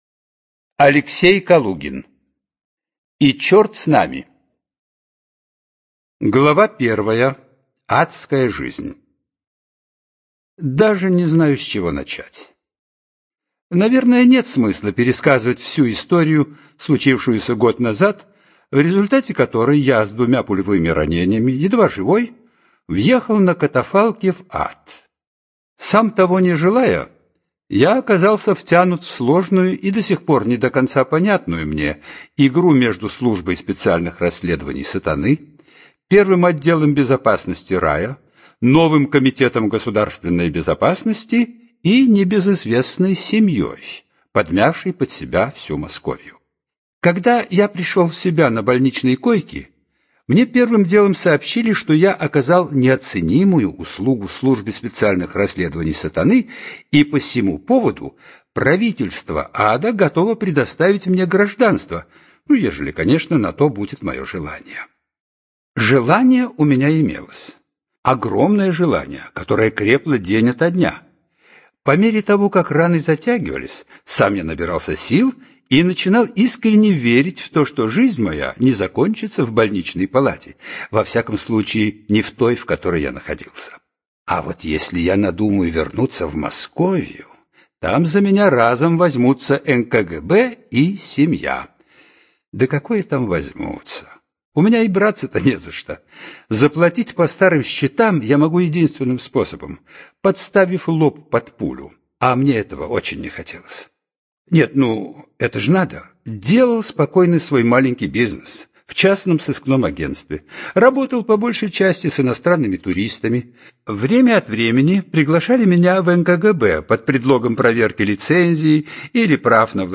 ЖанрФантастика, Детективы и триллеры, Боевики